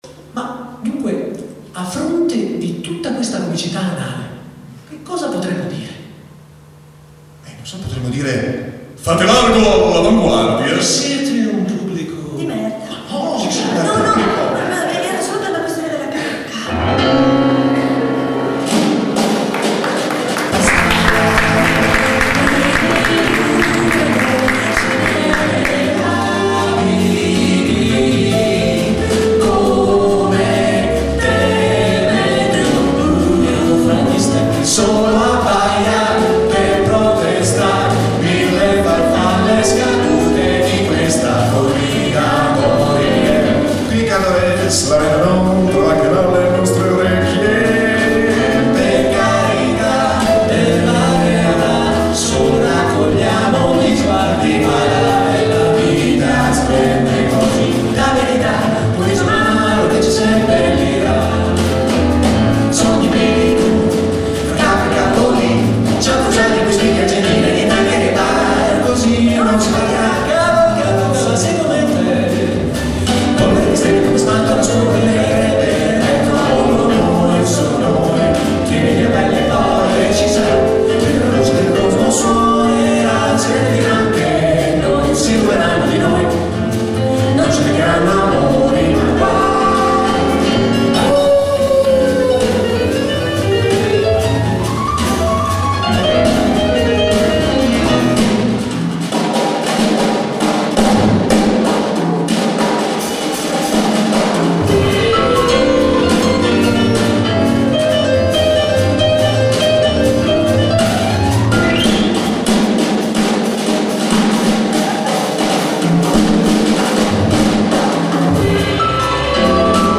tre cantanti/attori più tre musicisti
piano
Drums
Bass